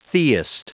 Prononciation du mot theist en anglais (fichier audio)
Prononciation du mot : theist